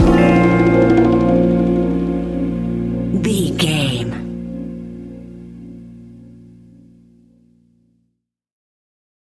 Fast paced
Uplifting
Ionian/Major
A♯